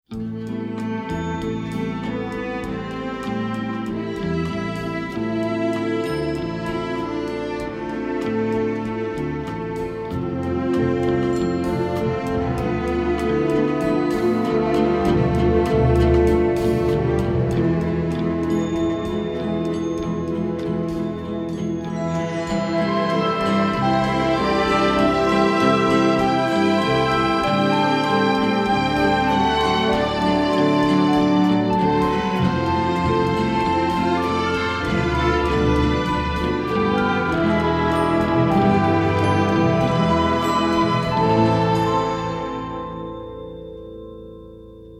beautiful pop-flavored score